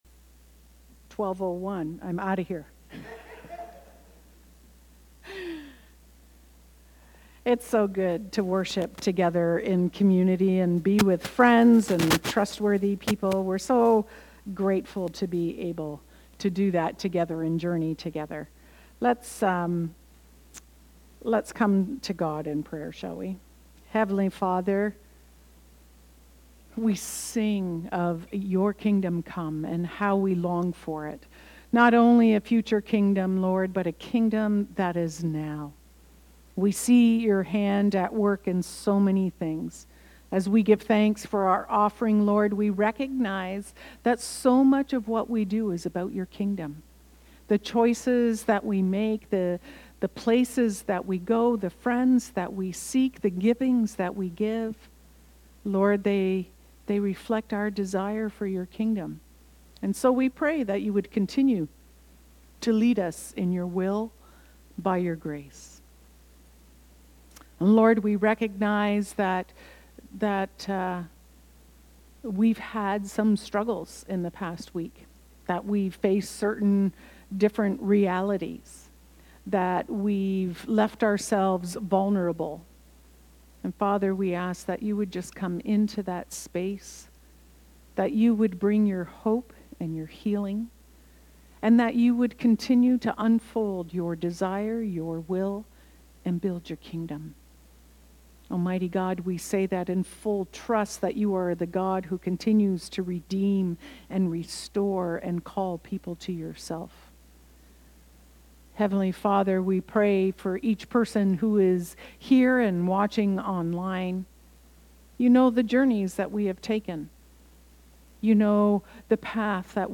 Knox Binbrook worship service July 20, 2025